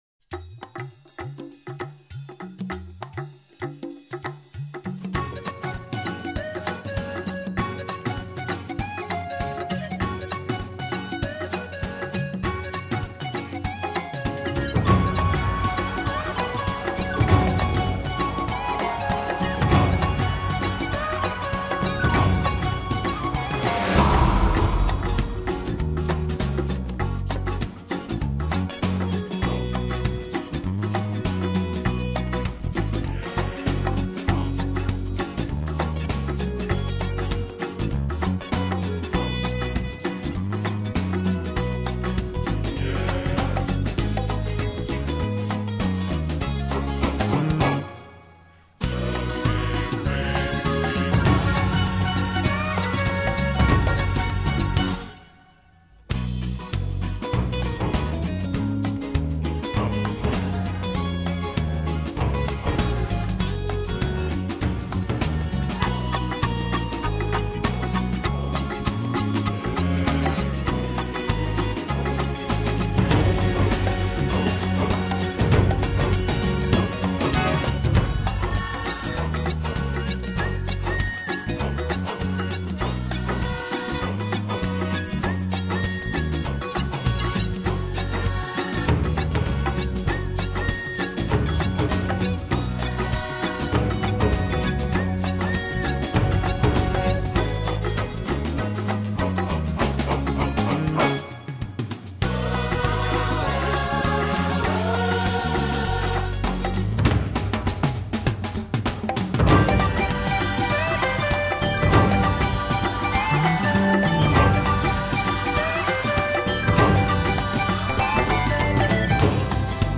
Версия с DVD